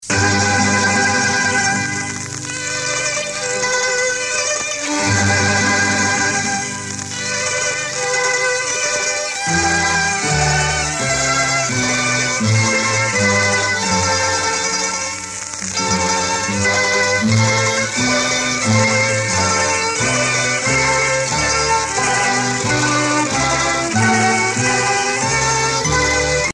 danse : diablada (Bolovie)
Pièce musicale éditée